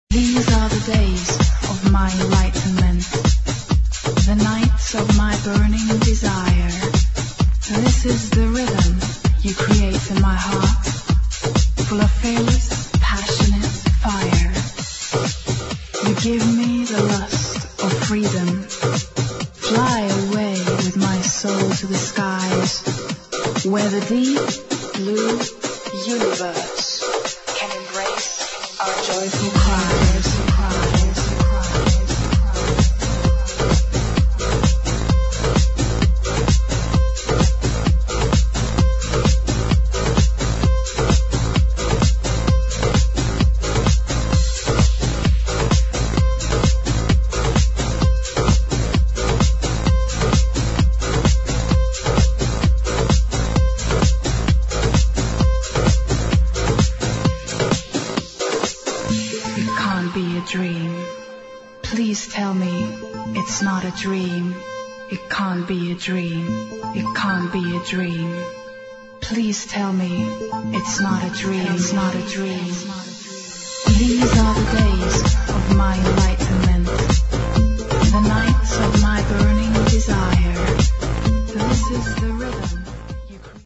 [ HOUSE / ELECTRO ]
フレンチ女性ヴォーカル・エレクトロ・ハウス・チューン！